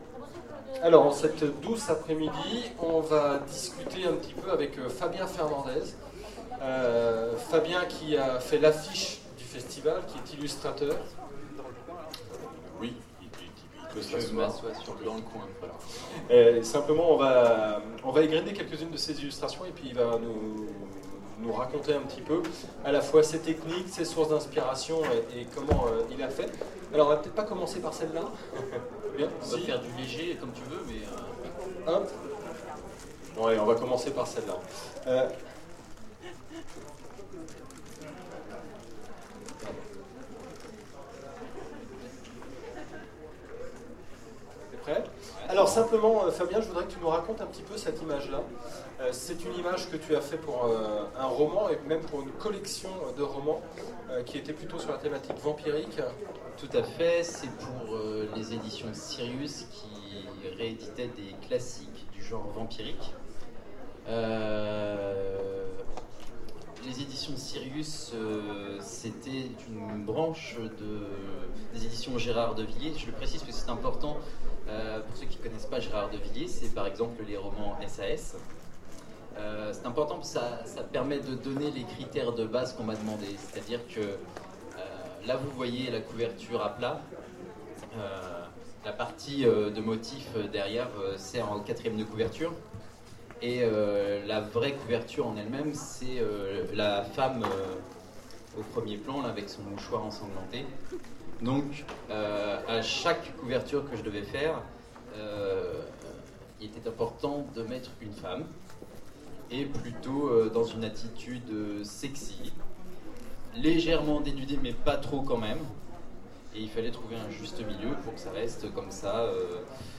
Autres Mondes Festival
Conférence